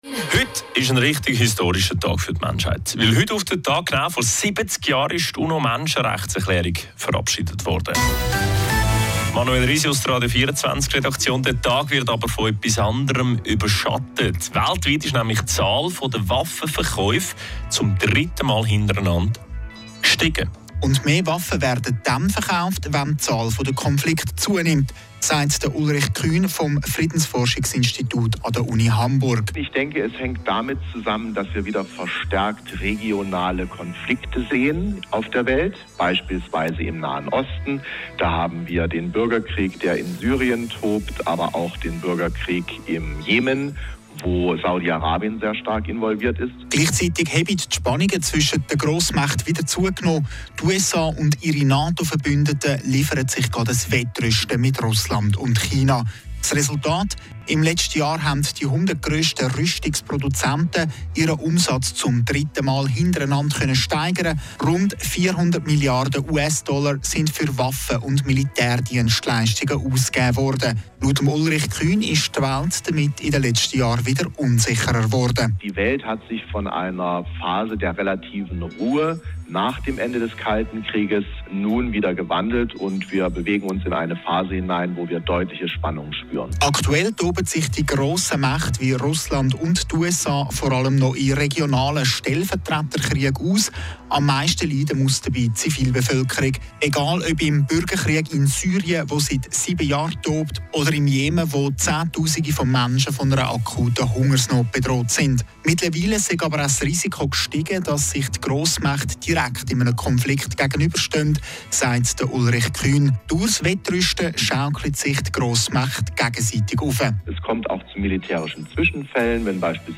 Anstieg der weltweiten Waffenproduktion – Interview mit dem Hörfunksender Radio 24